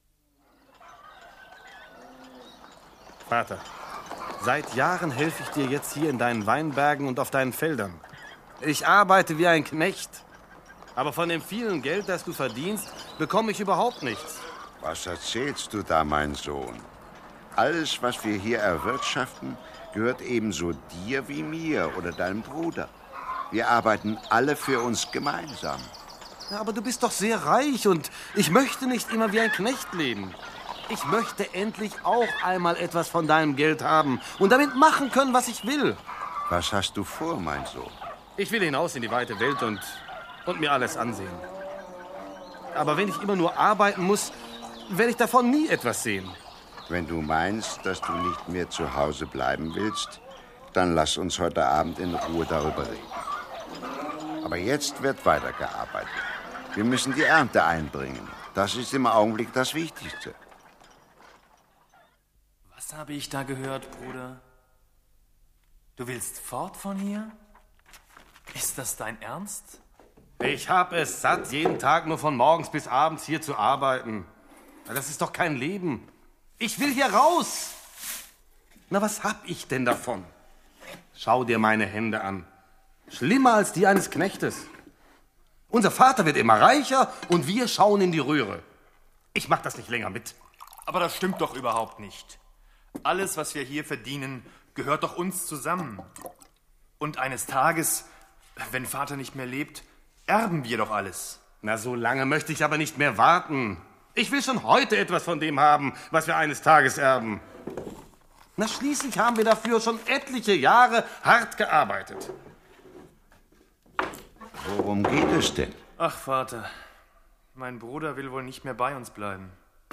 Der barmherzige Vater als Hörgeschichte!